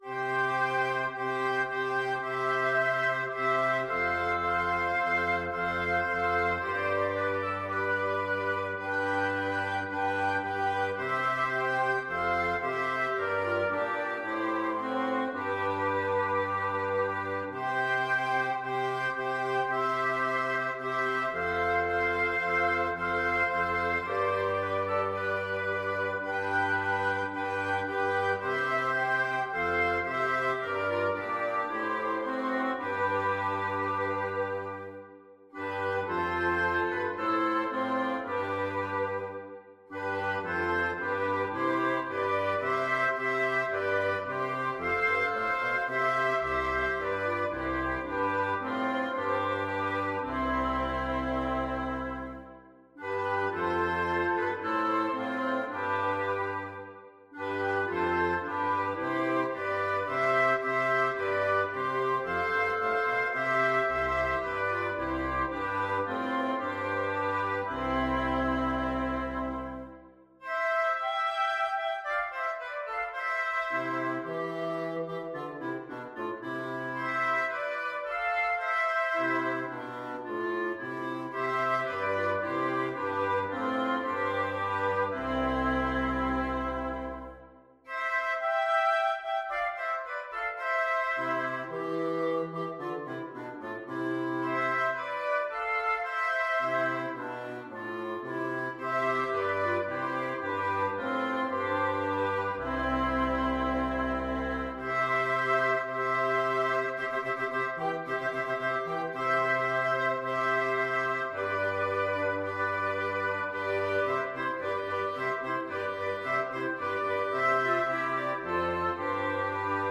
Free Sheet music for Wind Quartet
FluteOboeClarinetBassoon
C major (Sounding Pitch) (View more C major Music for Wind Quartet )
4/4 (View more 4/4 Music)
Classical (View more Classical Wind Quartet Music)
susato_danserye41_la_bataille_WQT.mp3